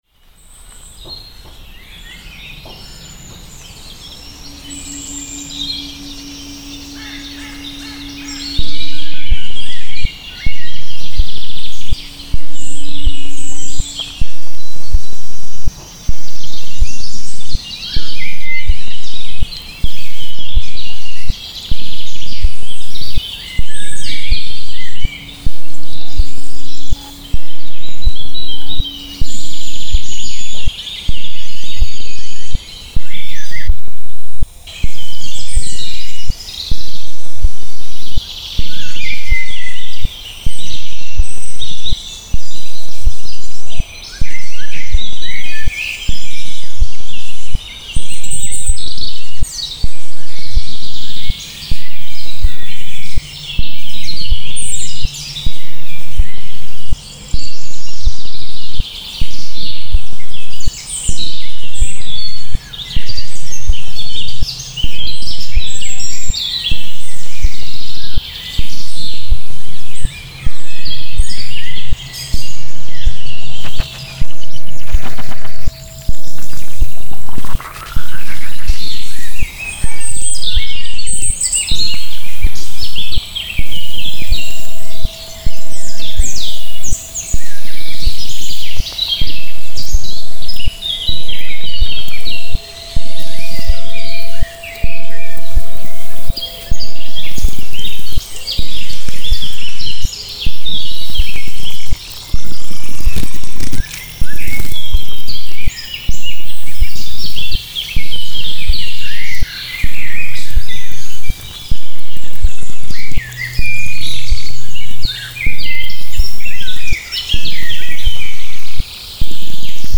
stereophonic composition